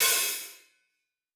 TC2 Live Hihat2.wav